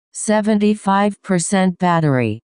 Play 75% Battery Warning - SoundBoardGuy
Play, download and share 75% Battery Warning original sound button!!!!
75-battery.mp3